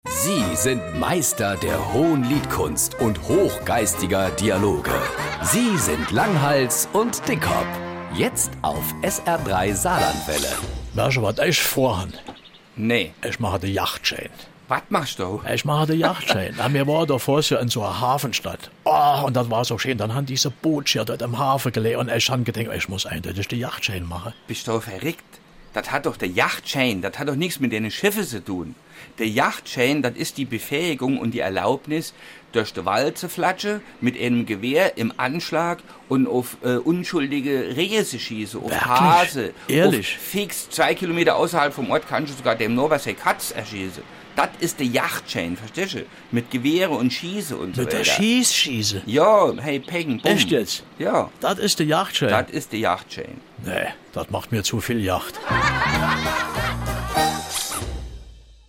Genres: Comedy